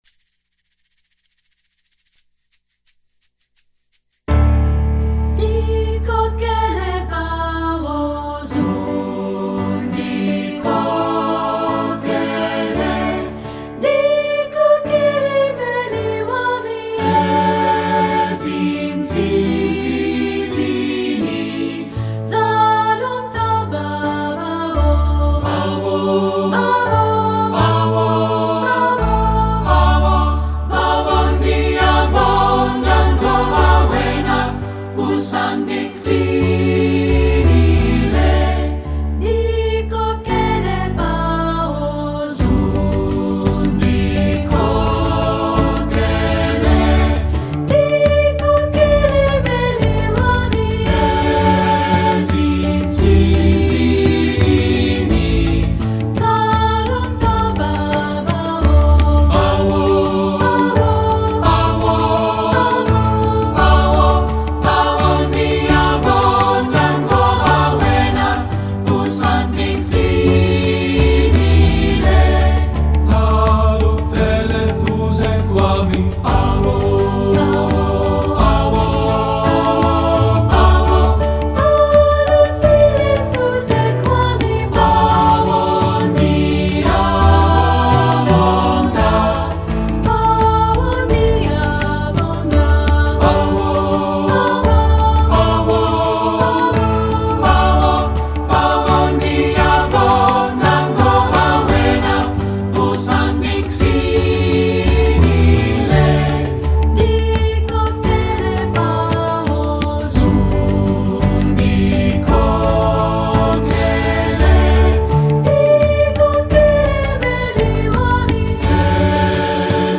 Composer: isiXhosa Folk Song
Voicing: 3-Part Mixed and Piano